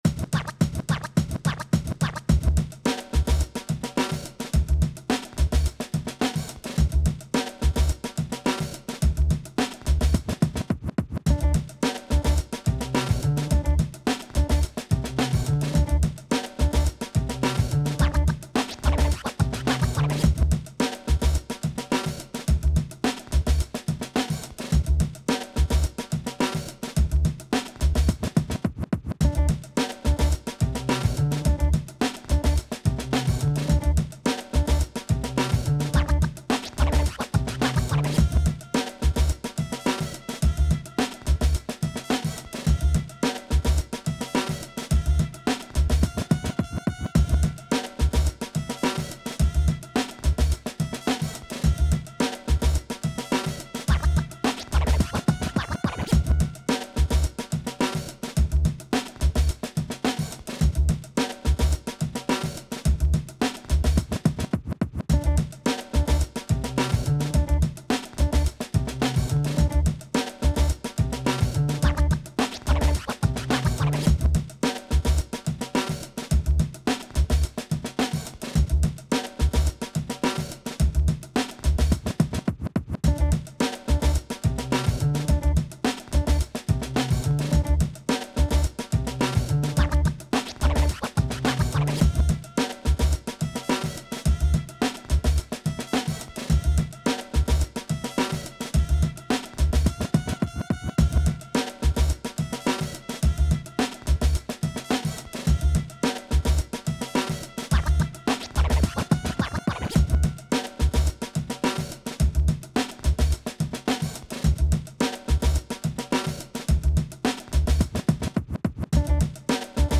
80s, Hip Hop
D maj